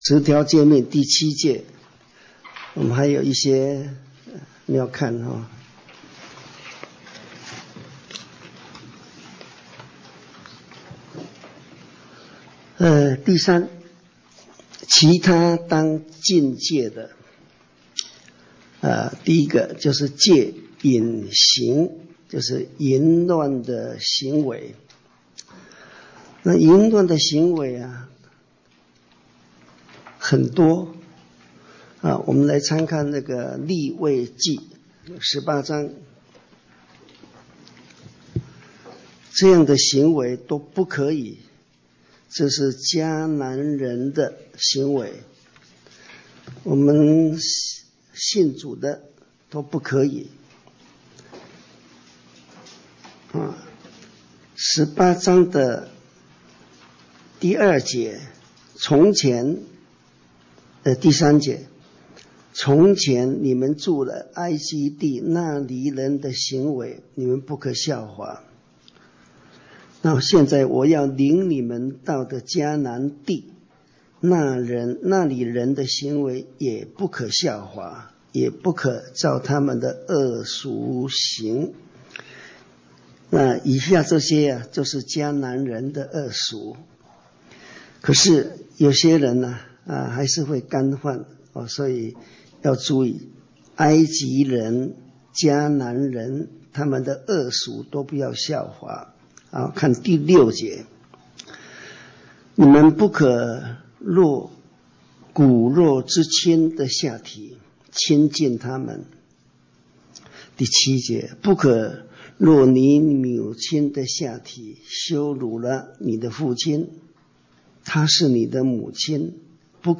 講習會
地點 台灣總會 日期 02/17/2014 檔案下載 列印本頁 分享好友 意見反應 Series more » • 出埃及記 22-1 • 出埃及記 22-2 • 出埃及記 22-3 …